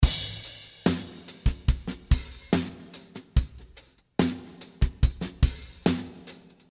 72 BPM - Golden Age (39 variations)
Classic Rock style, this product contains 39 loops with Cymbals and tom fills. Because it is in 72 bpm the snare have long reverb. Although the beat is slow you will Find that the loops are very accurate.